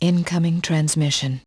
youvegotmail.wav